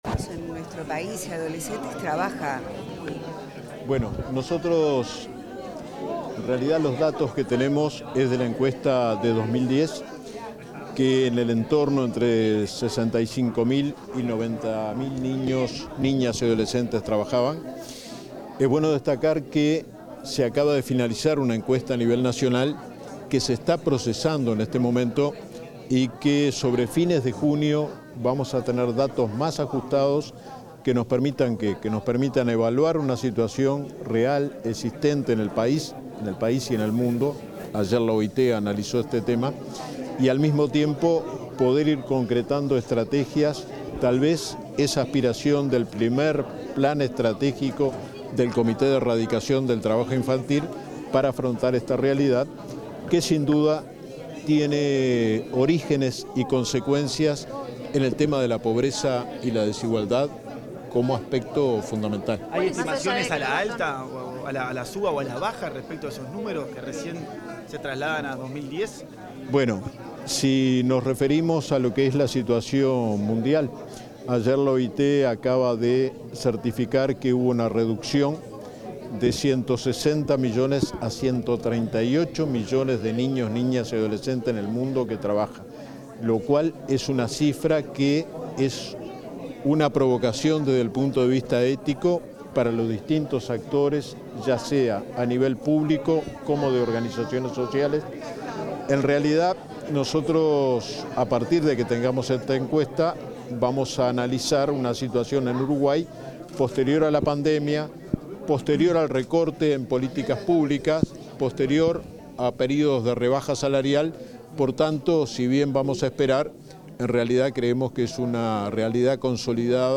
Declaraciones del inspector general del Trabajo y la Seguridad Social, Luis Puig 12/06/2025 Compartir Facebook X Copiar enlace WhatsApp LinkedIn En el marco del Día Mundial contra el Trabajo Infantil, el inspector general del Trabajo y la Seguridad Social, Luis Puig, efectuó declaraciones a la prensa.